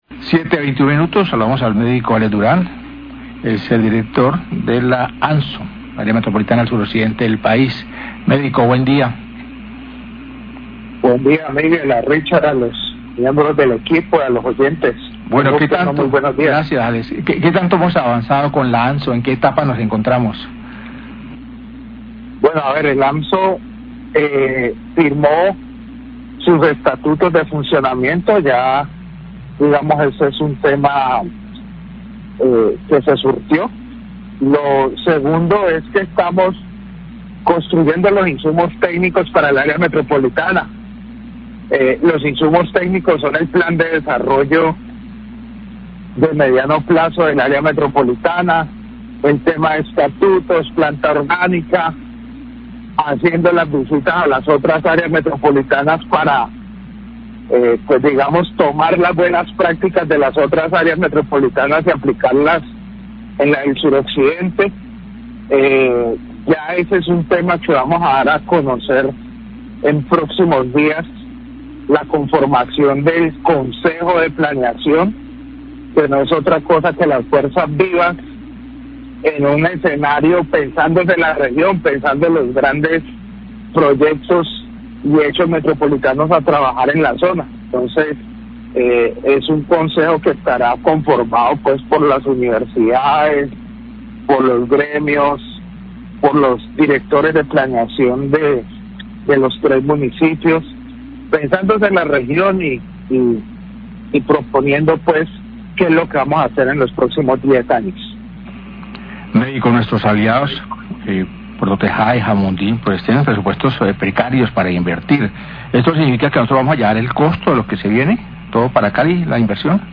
Radio
Director de la AMSO, Alexander Durán, habló acerca del avance de la AMSO donde ya se firmaron los estatutos de funcionamiento y de la construcción de los insumos técnicos para el área metropolitana como el plan de desarrollo para el mediano plazo y realizar una visita a otras áreas metropolitanas para conocer las cosas buenas que se pueden aplicar a la AMSO.